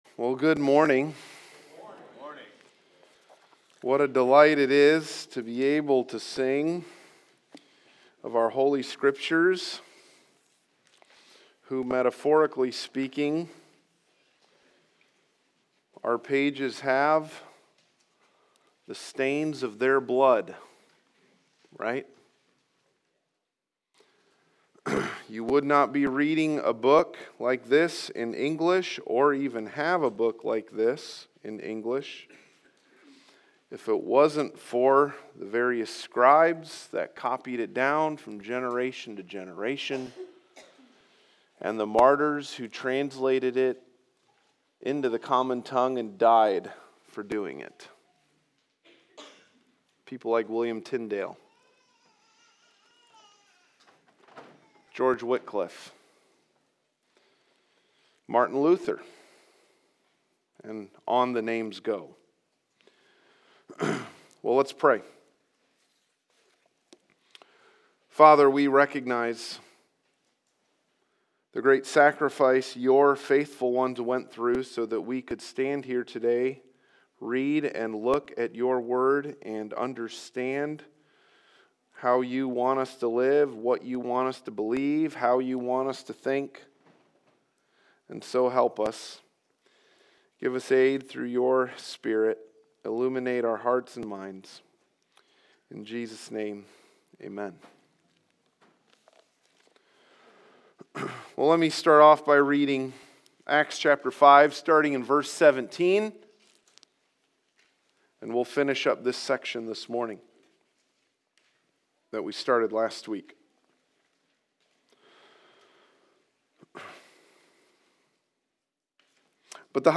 Passage: Acts 5:17-42 Service Type: Sunday Morning